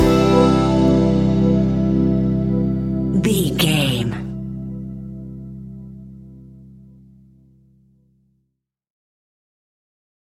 Epic / Action
Uplifting
Ionian/Major